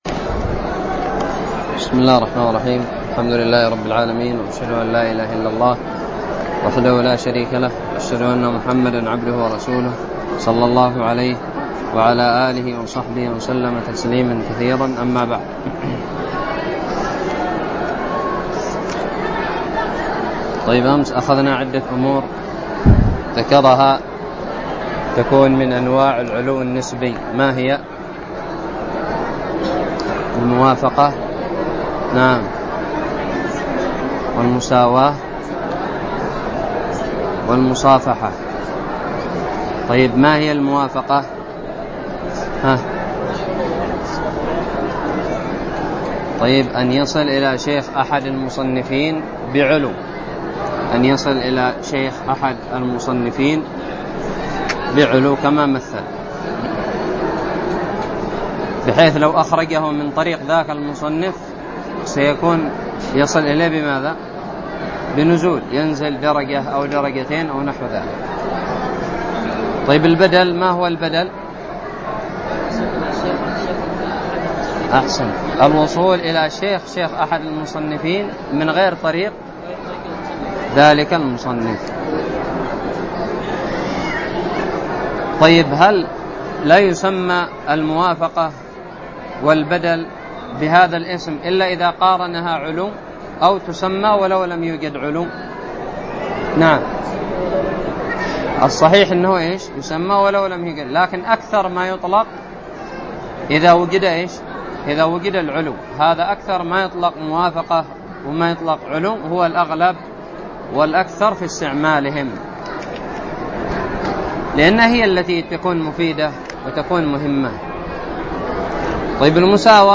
الدرس الحادي والأربعون من شرح كتاب نزهة النظر
ألقيت بدار الحديث السلفية للعلوم الشرعية بالضالع